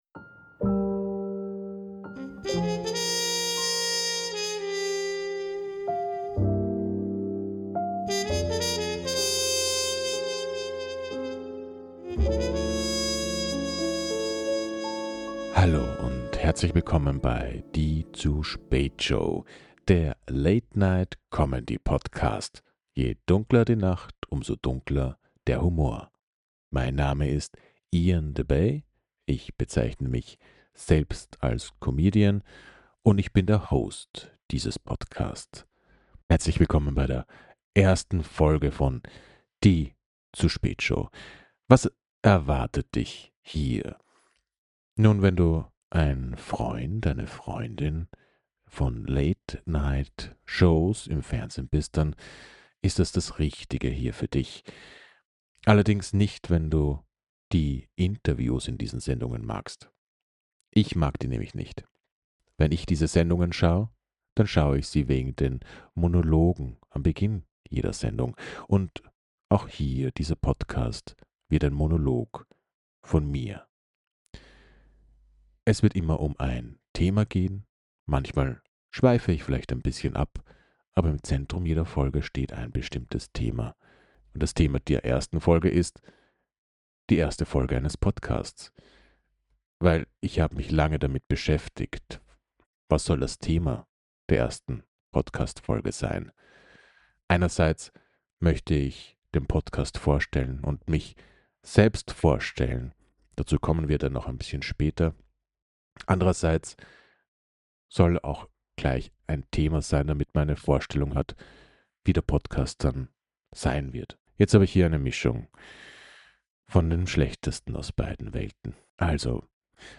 Ein ruhiger, schwarzhumoriger Monolog, der sich jedes Mal ein Thema vornimmt. Keine News, keine Gäste, keine künstlich empörten Debatten.